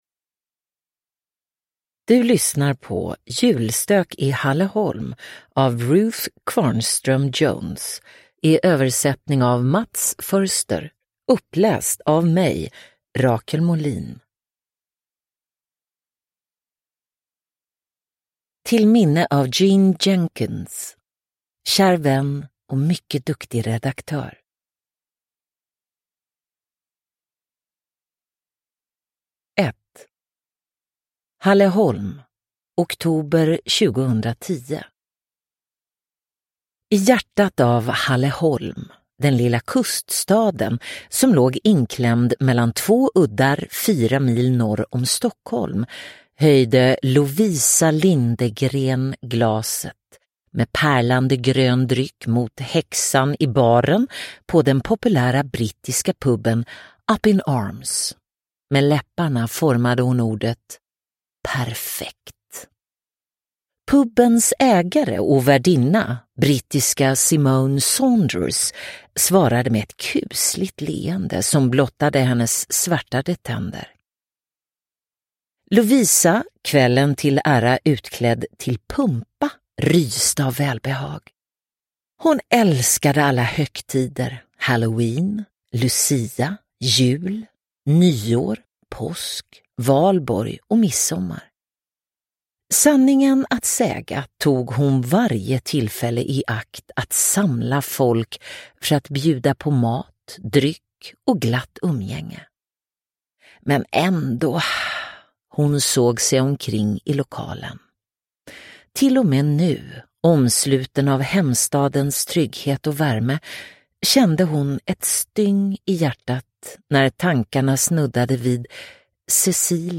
Julstök i Halleholm (ljudbok) av Ruth Kvarnström-Jones